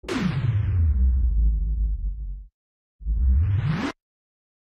Подборка включает плавные переходы, растянутые ноты и эмбиент-композиции.
Замедление и последующее ускорение